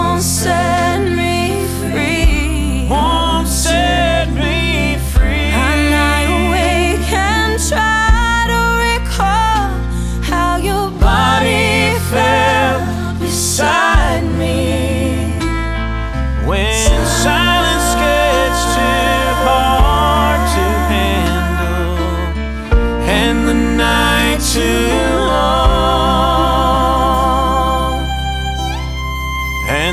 Genre: Holiday